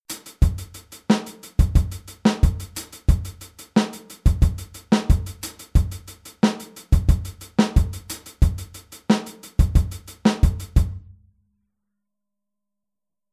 Aufteilung linke und rechte Hand auf HiHat und Snare